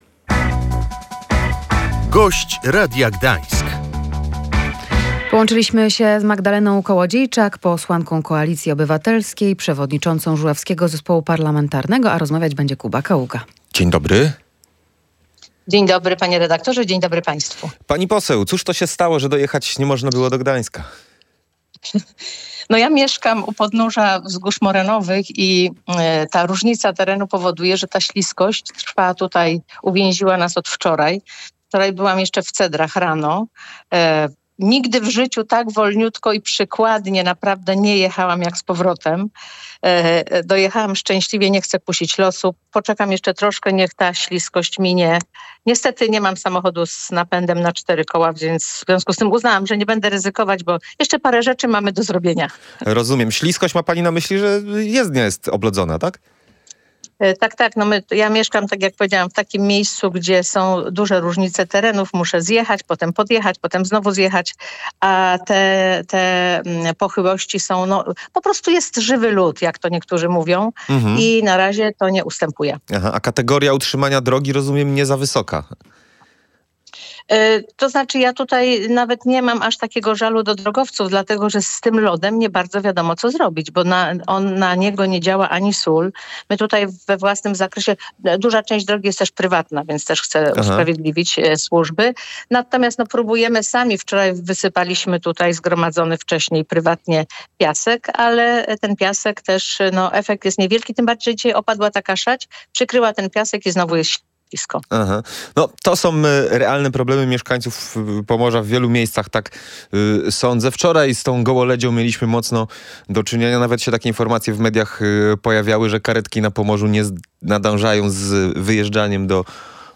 Dodatkowe 40 milionów złotych trafi w tym roku na Żuławy – mówiła w Radiu Gdańsk posłanka Magdalena Kołodziejczak, przewodnicząca Żuławskiego Zespołu Parlamentarnego. Środki potrzebne są na bieżące utrzymanie infrastruktury i wałów.